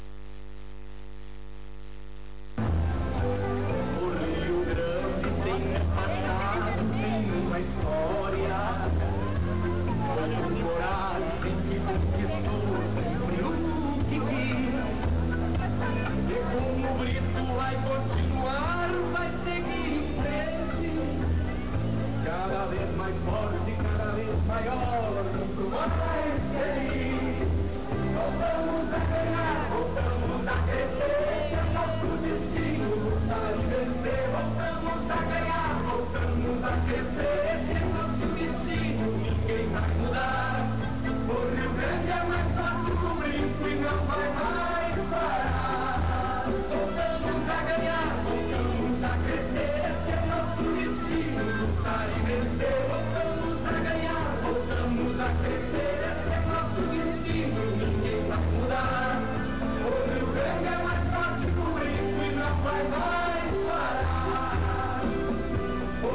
Noch eine akustische Impression.
Parteilied für Britto Parteilied für Olivio Marsch Wahl Hier wird schon elektronisch online gewählt!
Bundeswahl 1998 Bundeswahl in Brasilien, Wahlkampfveranstaltung in Santa Maria mit Werbung für Britto und Olivio .